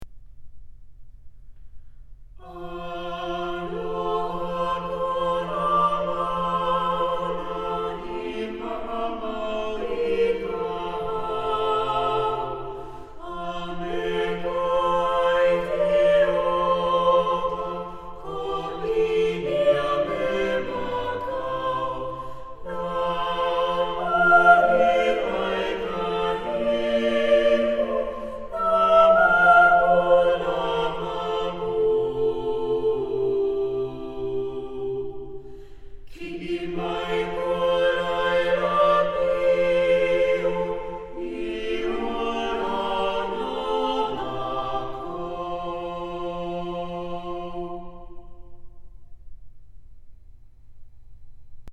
An inspiring mission hymn.
SAB a cappella